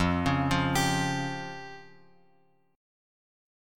Fsus2#5 chord {1 4 3 x x 3} chord